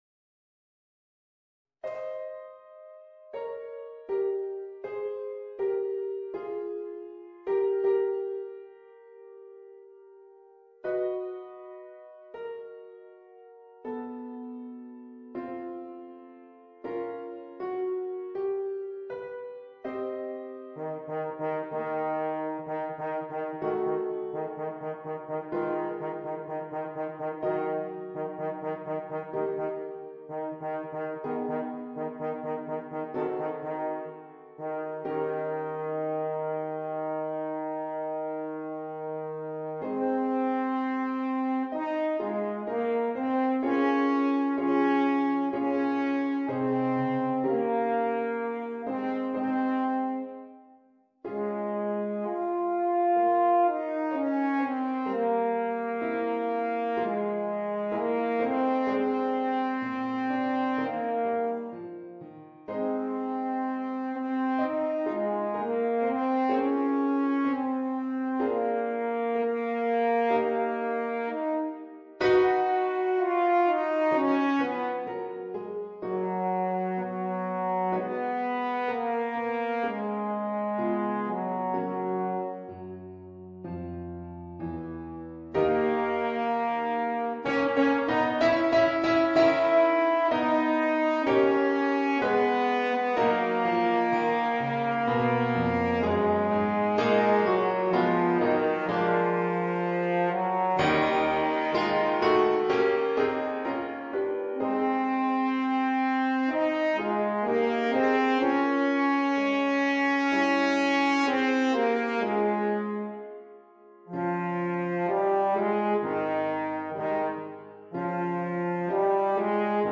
Voicing: Horn and Piano